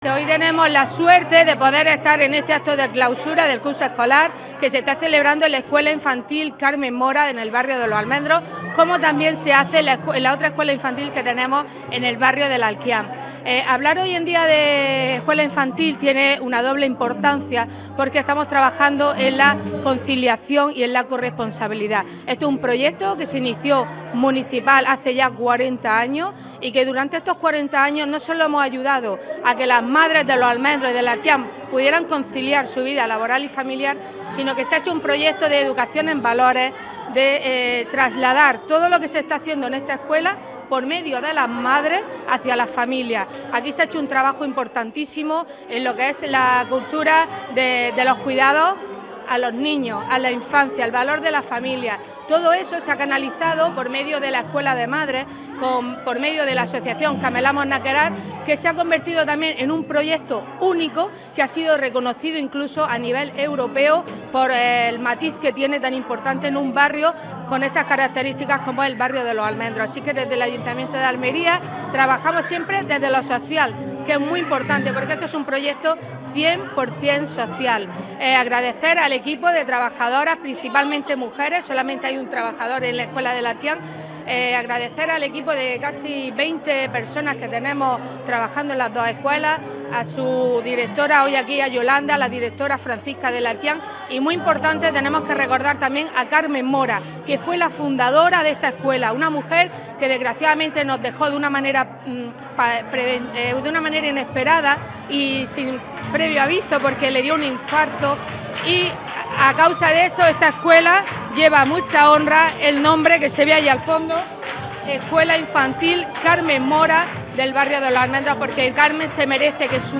La concejala Paola Laynez asiste a la fiesta de verano de este centro educativo, que, junto a la asociación de madres, apoya tanto a los menores como a sus progenitoras
Así lo ha expresado la concejala de Familia, Inclusión e Igualdad, Paola Laynez, que ha acompañado a madres, padres e hijos en esta actividad.